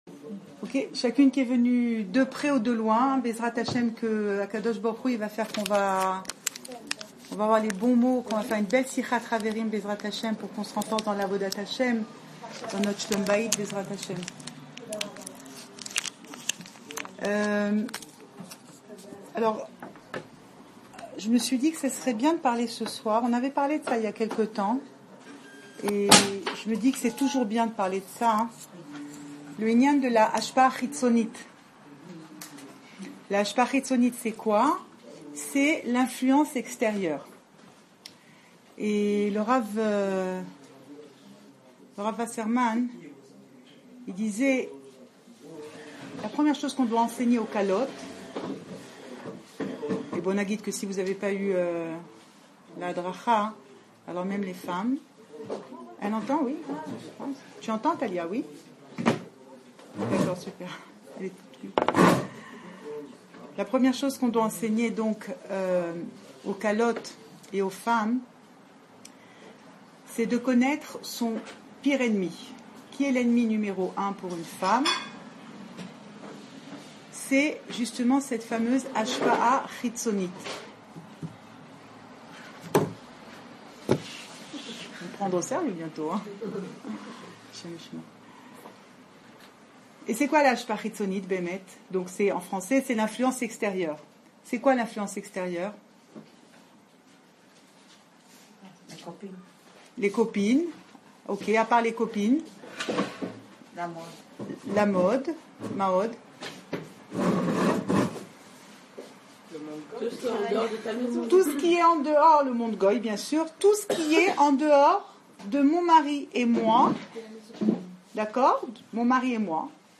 Enregistré à Guivat Chaoul, Jérusalem